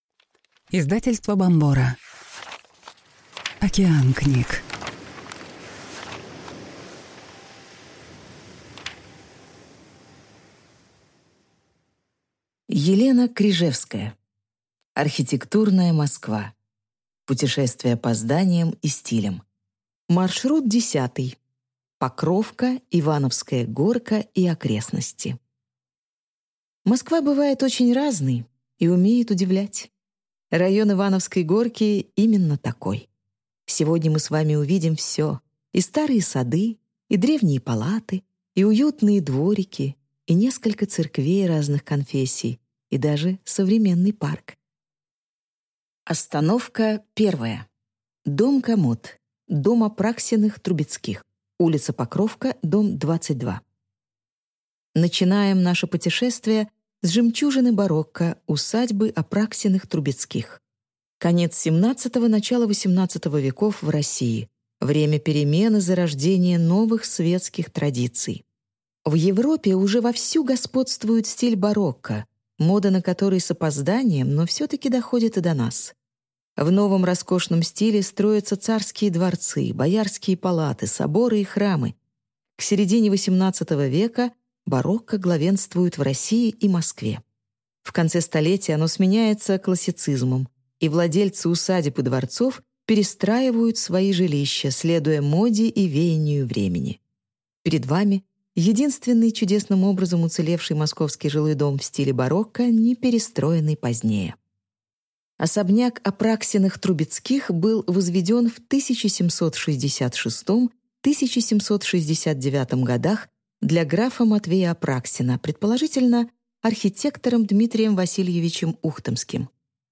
Аудиокнига Покровка, Ивановская горка и окрестности | Библиотека аудиокниг